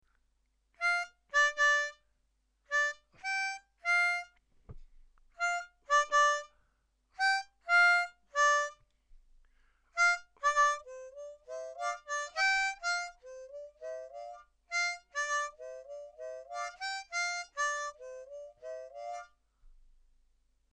Just one line here; no harmony.